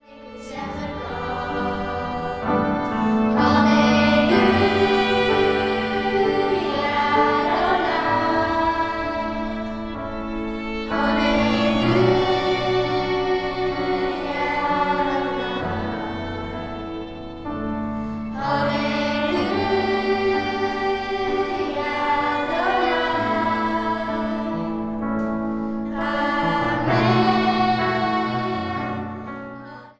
piano
viool.
Zang | Kinderkoor